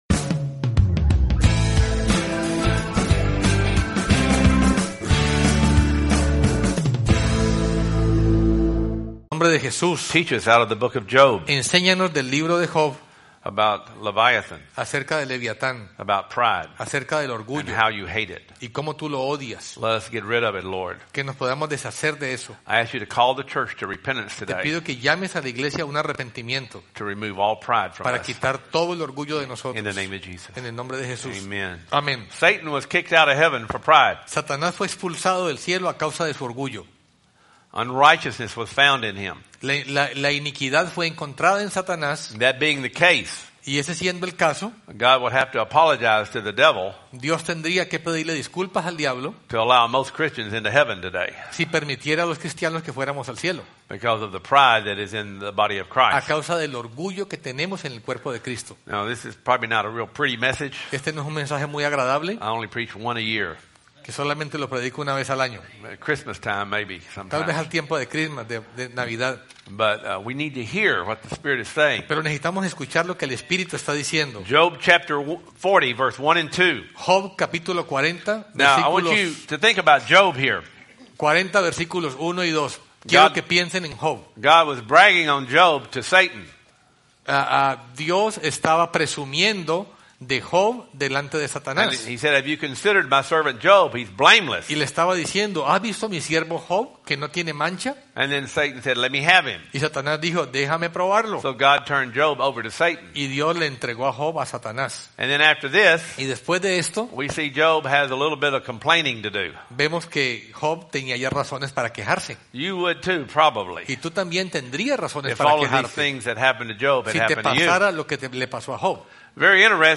2011 Sunday Services Service Type: Sunday Service « Eyes That Don’t See